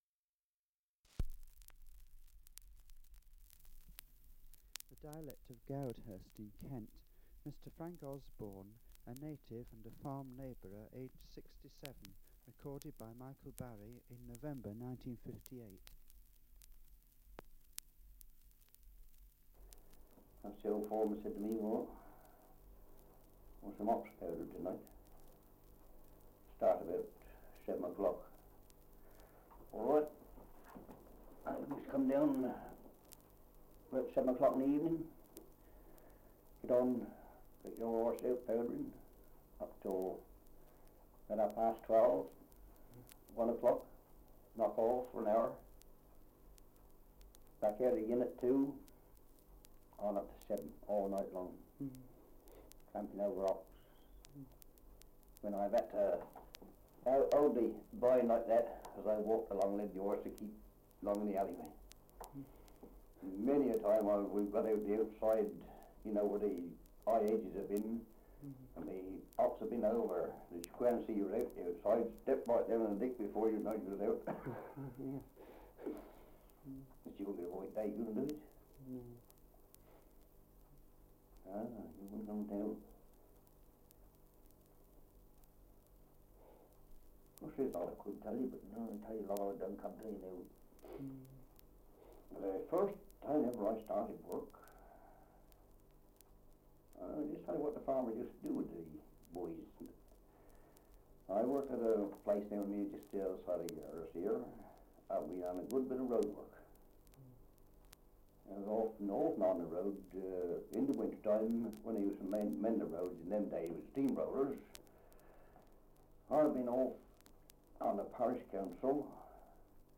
Survey of English Dialects recording in Goudhurst, Kent
78 r.p.m., cellulose nitrate on aluminium